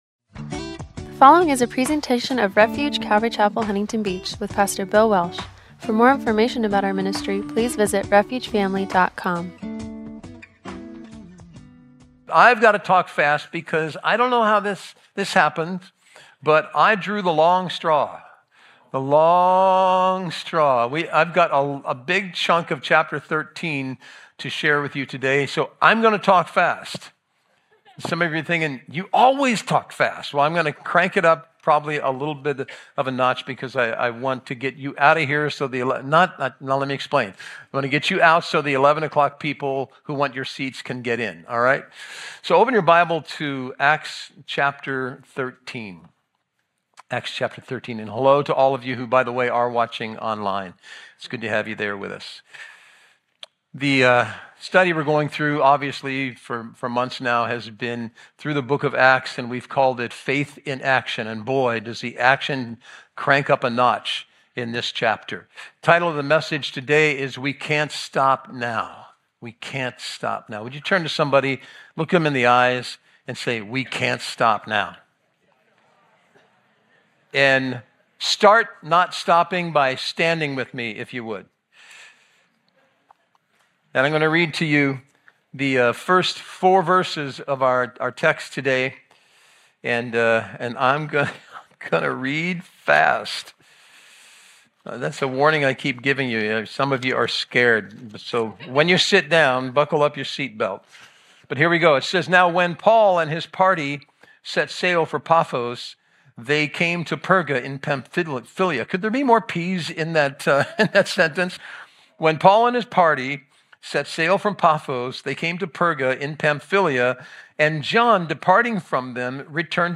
“We Can’t Stop Now”-Acts 13:13-52 – Audio-only Sermon Archive
Passage: Acts 13:13-52 Service Type: Sunday Morning